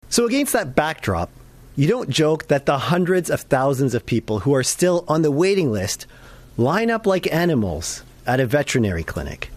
Liberal Health Critic Adil Shamji held a press conference and addressed this, noting the many Ontarians who have died waiting for these scans and surgeries.